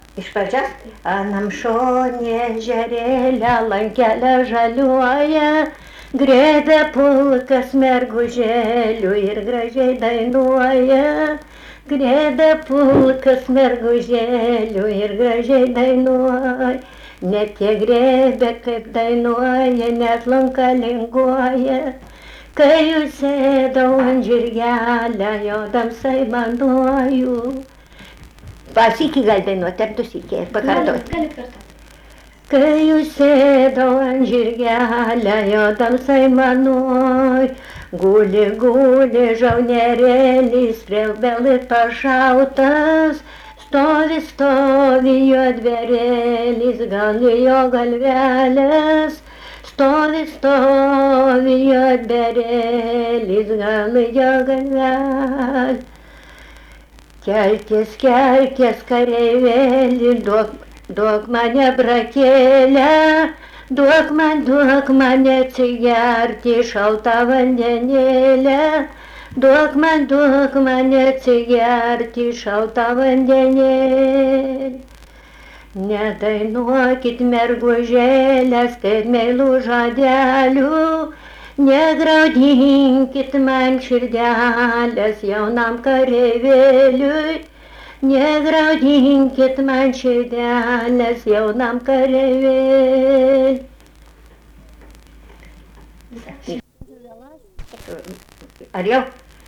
Dalykas, tema daina
Erdvinė aprėptis Laibgaliai
Atlikimo pubūdis vokalinis